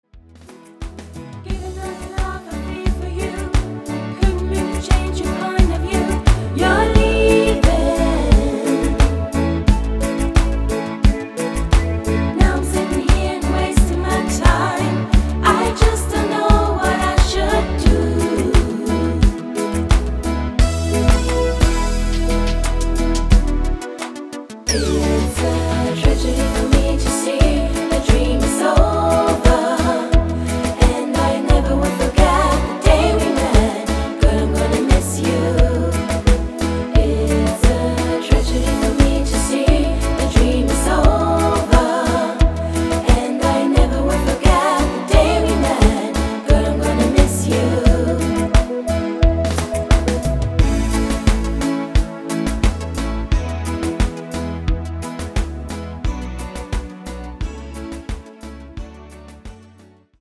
Reggae Version